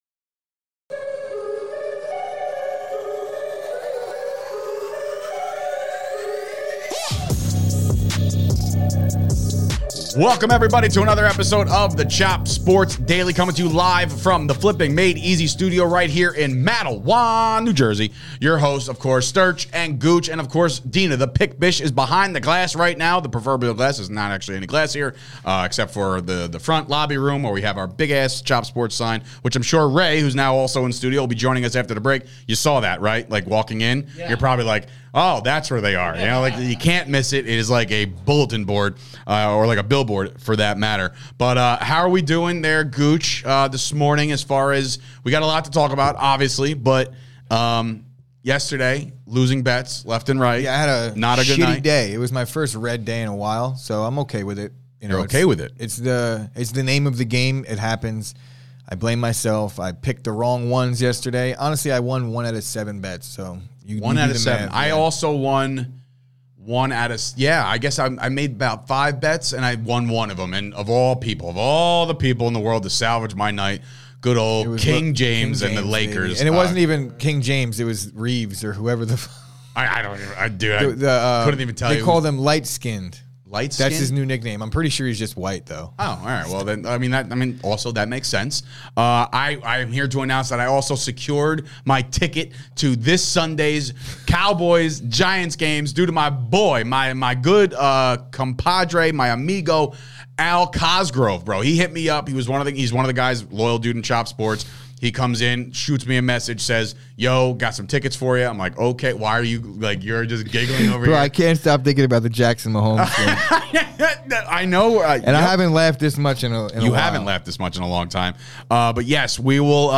The Chop Sports Daily is back coming to you LIVE from the Flipping Made EZ Studio in Matawan, NJ!